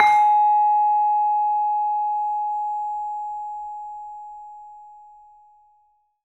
LAMEL G#4 -L.wav